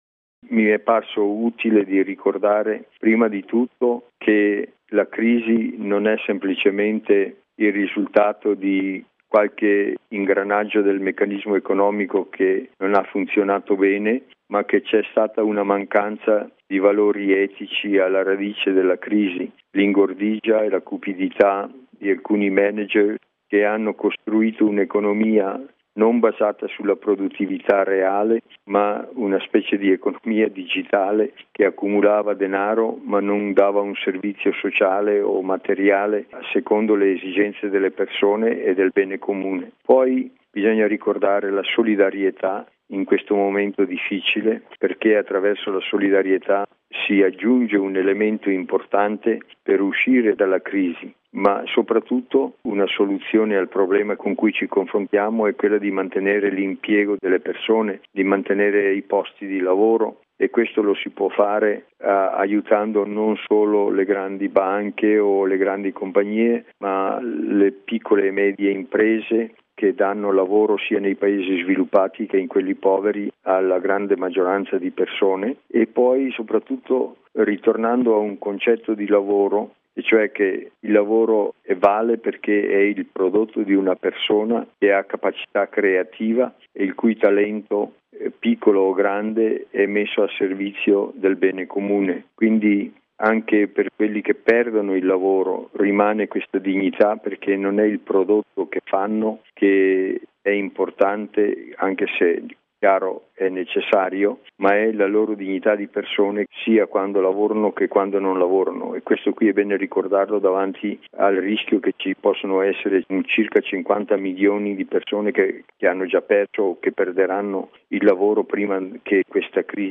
◊   “L’attuale crisi economica e finanziaria impone misure concrete per indirizzare e cambiare comportamenti, regole e valutazioni erronee" che l'hanno causata: lo ha sottolineato l’arcivescovo Silvano Tomasi, osservatore permanente della Santa Sede, presso l’ONU di Ginevra, intervenuto ieri alla Conferenza annuale dell’Organizzazione internazionale del lavoro (Ilo), che vede riuniti nella città elvetica, dal 3 al 19 giugno, circa 4 mila delegati di governi e rappresentanti del mondo dell’impreditoria e dei lavoratori, interpellati dall’impatto della crisi sull’occupazione. Il pensiero di mons. Tomasi